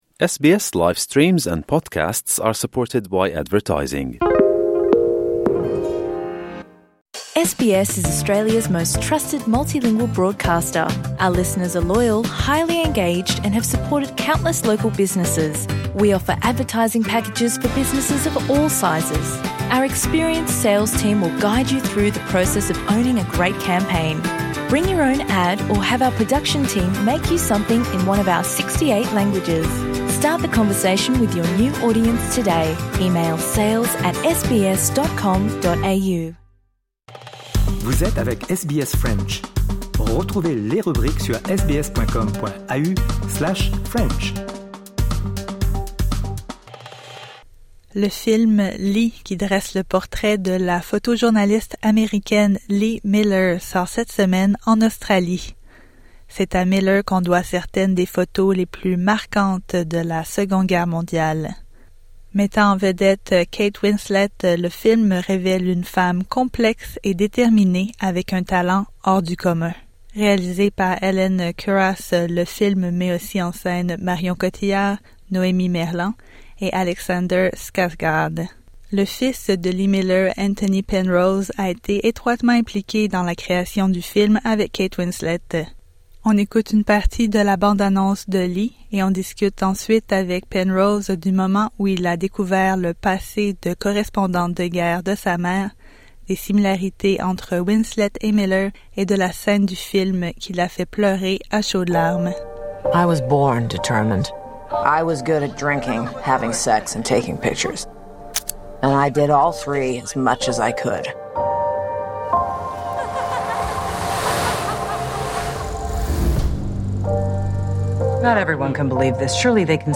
Voyez la bande-annonce de LEE ici: Rediffusion : Cette interview avait d'abord été diffusée le 22 octobre 2024.